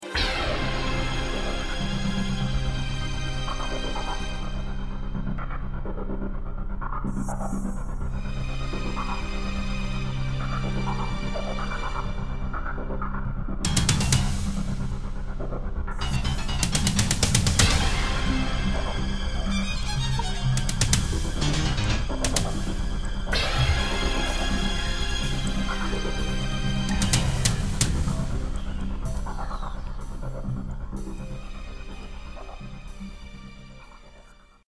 Australian Trailer soundtrack
Composition, arrangement and performance.